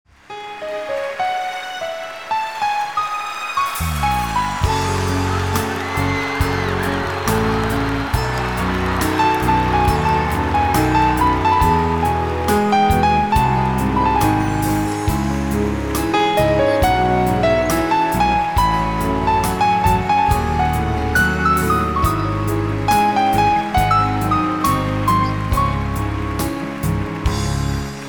Мелодия / Проигрыш